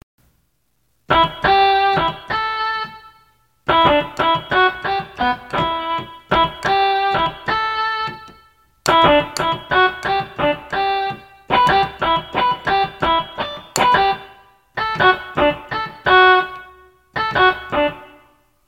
집에 있는 신디로 녹음해봤습니다